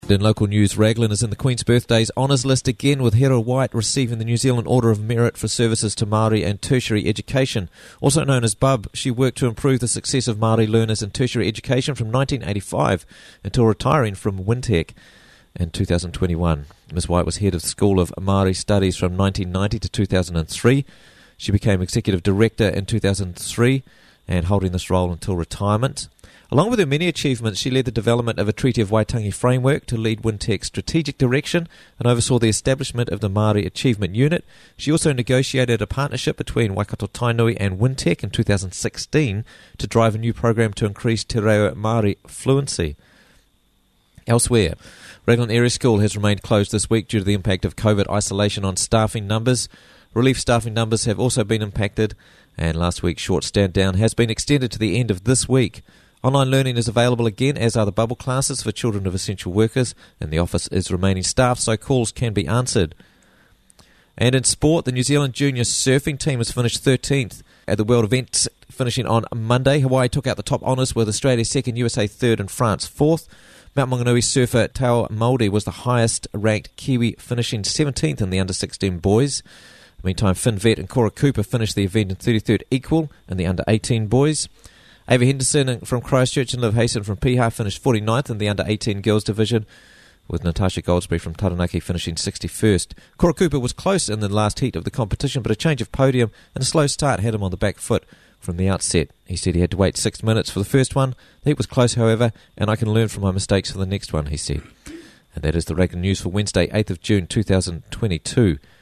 Raglan News for Wednesday 8th June 2022 - Raglan News Bulletin